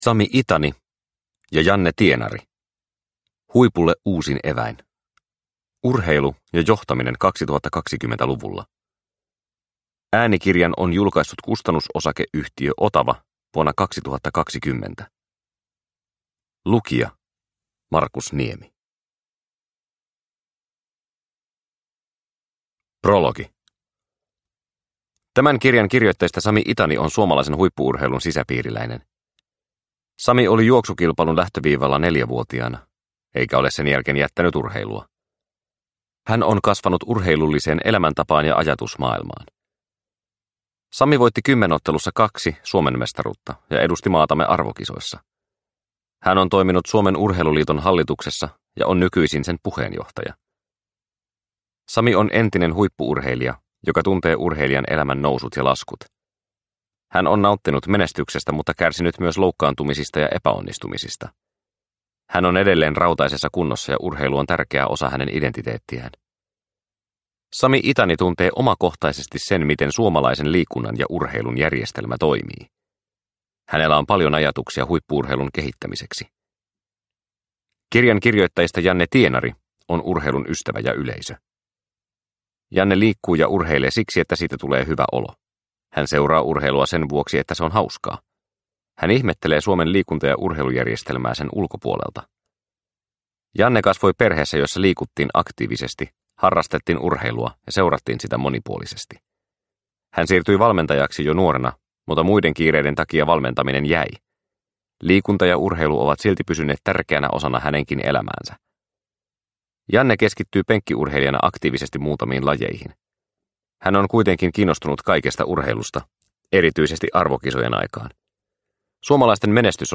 Huipulle uusin eväin – Ljudbok – Laddas ner